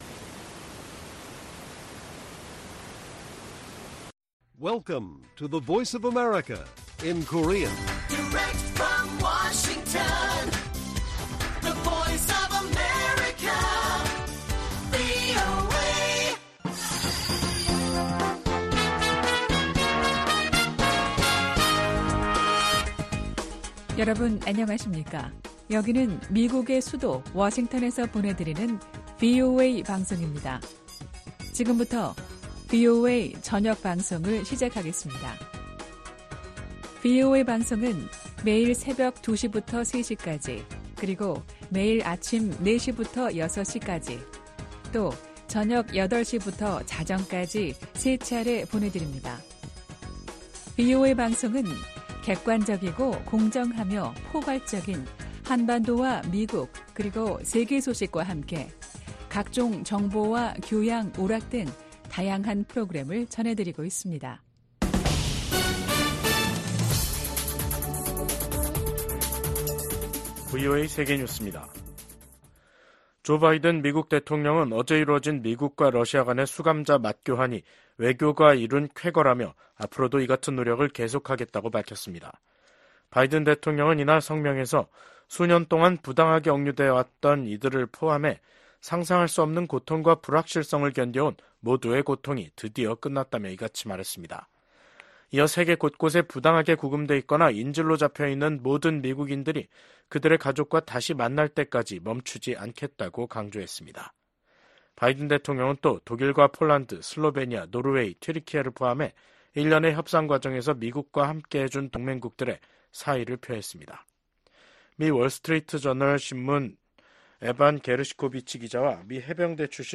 VOA 한국어 간판 뉴스 프로그램 '뉴스 투데이', 2024년 8월 2일 1부 방송입니다. 중국, 러시아, 북한이 계속 핵전력을 증대한다면 미국은 핵 태세와 규모를 조정할 수도 있다고 미 국방부 고위관리가 밝혔습니다. 국무부는 북한의 대남 오물 풍선 살포를 무모하고 불안정한 ‘도발’로 규정했습니다. 한국 정부가 집중호우로 심각한 피해를 입은 북한에 인도적 지원을 전격 제안했습니다.